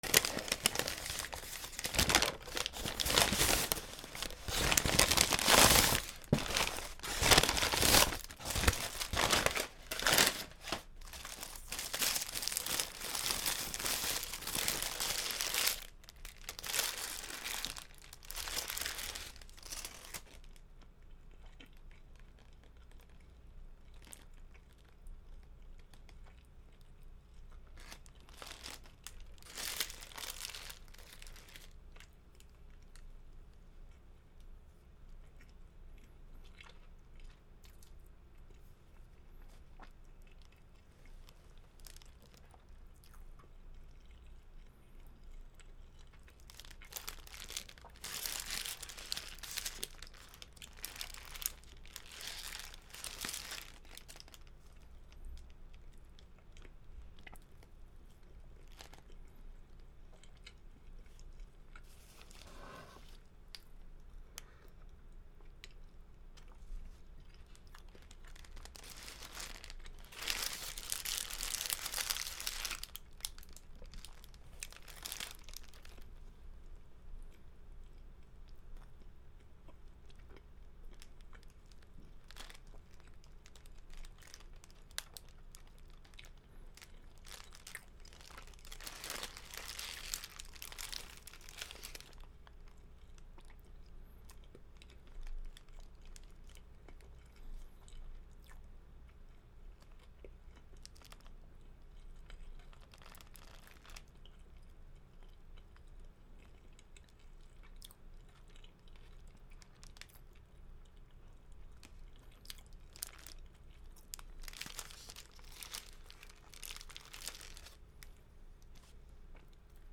ハンバーガーを食べる(テイクアウト)
C414